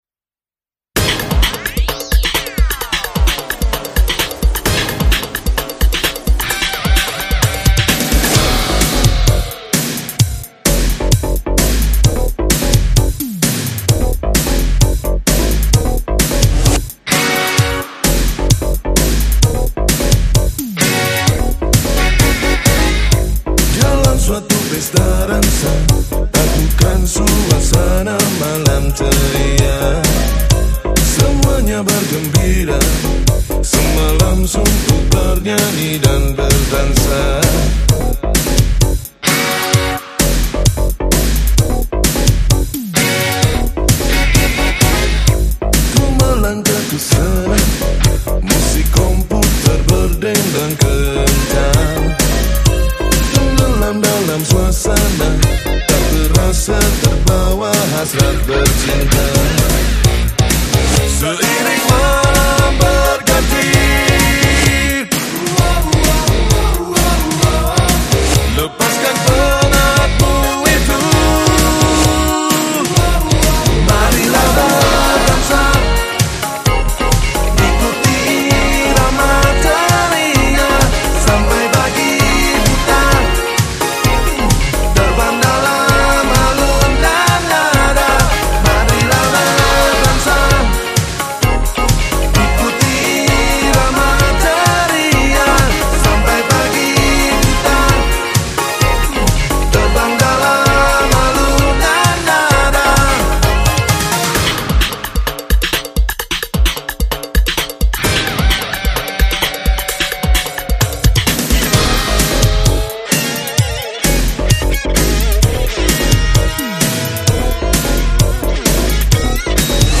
Malang Electronic